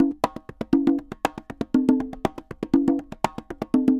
Congas_Salsa 120_4.wav